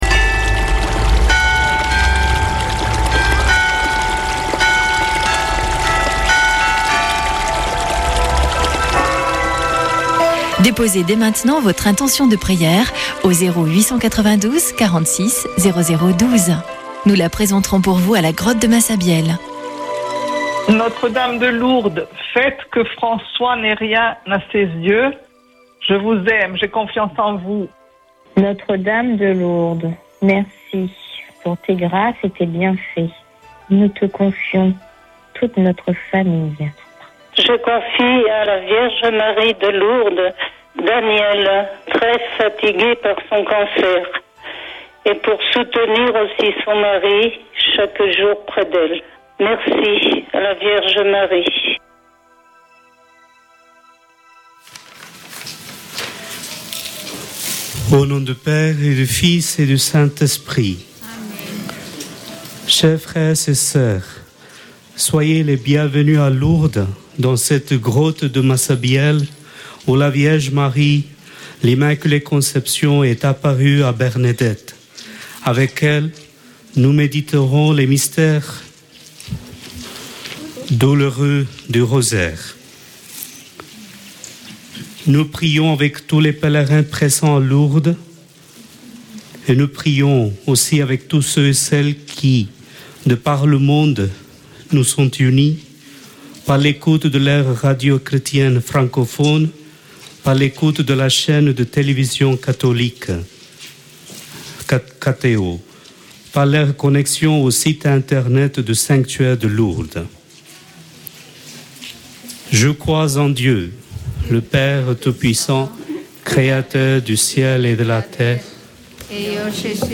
Chapelet de Lourdes du 05 déc.
Une émission présentée par Chapelains de Lourdes